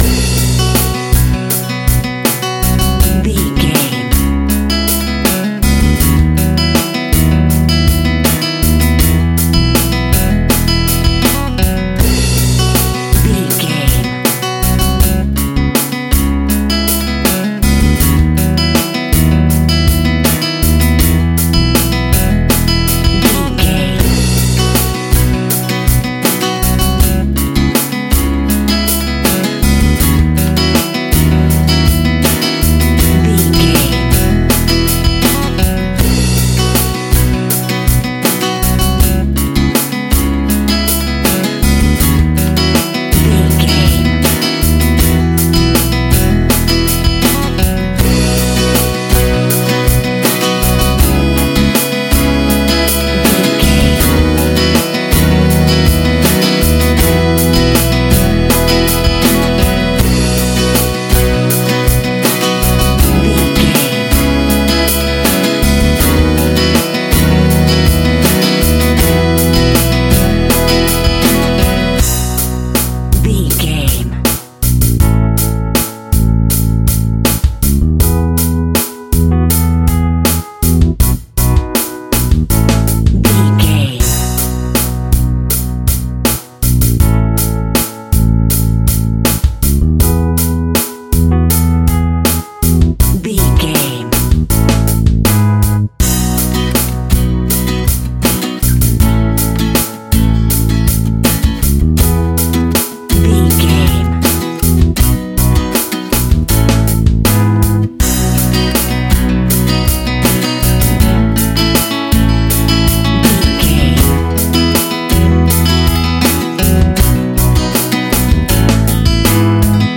Acoustic Pop Rock Theme.
Ionian/Major
acoustic rock
happy
uplifting
soft rock
finger pick
harmonica
drums
bass guitar
acoustic guitar
synth keys